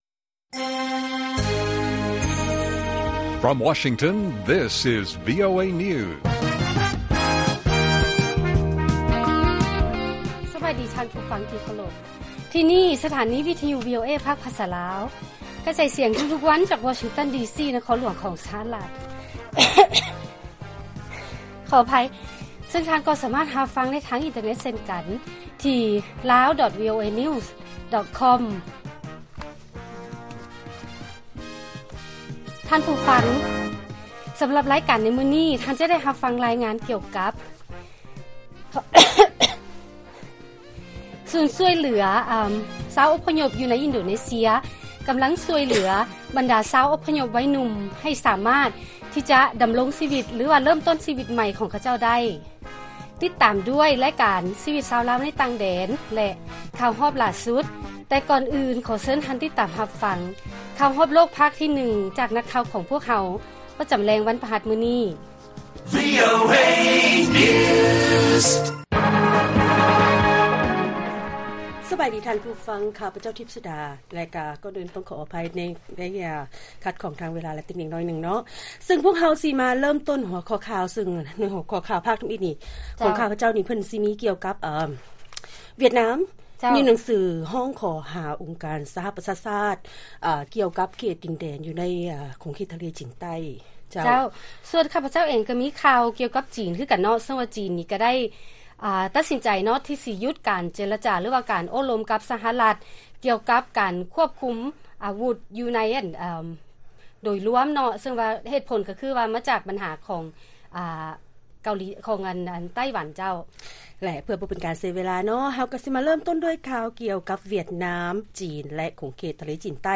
ລາຍການກະຈາຍສຽງຂອງວີໂອເອ ລາວ: ສູນຮຽນຮຸ້ໃນອິນໂດເນເຊຍ ຈະຊ່ວຍເຫຼືອອົບພະຍົບໄວໜຸ່ມ ໃນການກະກຽມເພື່ອເລີ້ມຕົ້ນຊີວິດໃໝ່